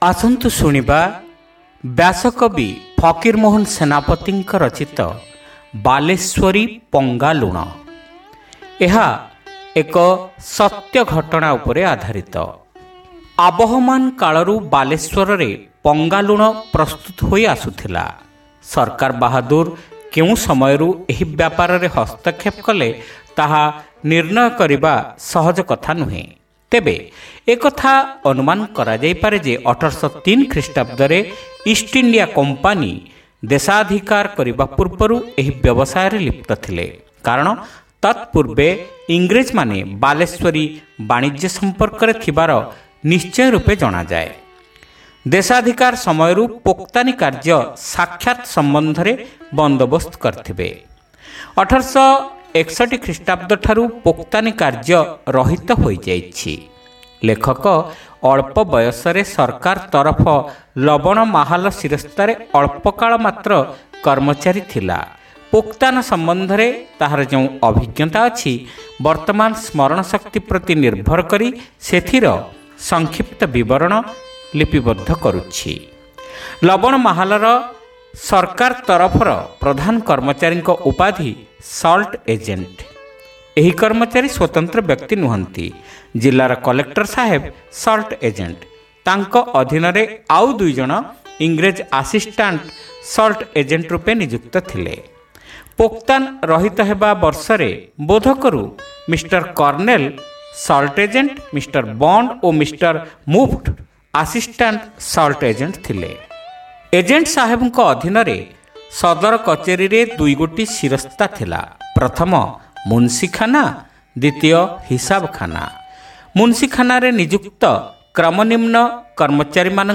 Audio Story : Baleswari Panga Luna